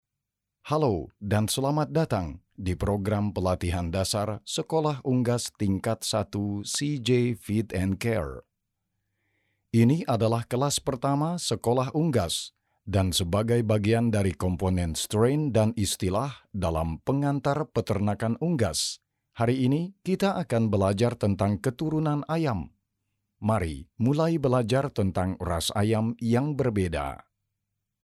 Commercieel, Diep, Natuurlijk, Veelzijdig, Zakelijk
E-learning